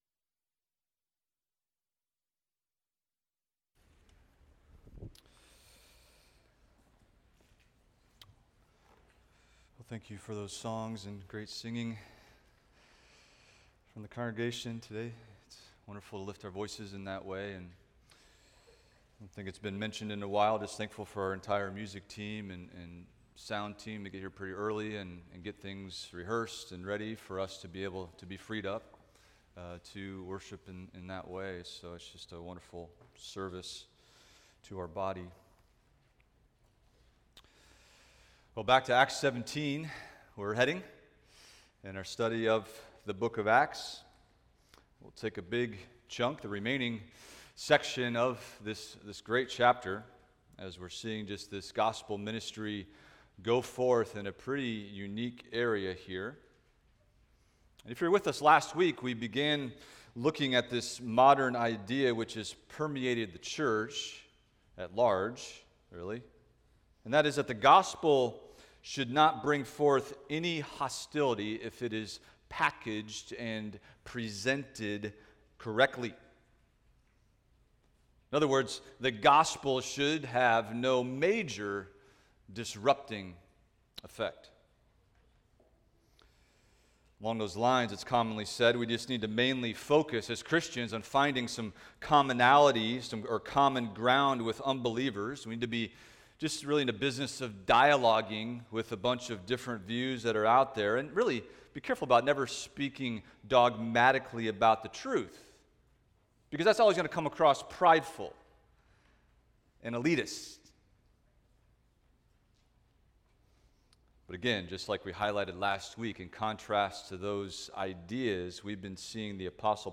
Expository Preaching about the Birth of Christ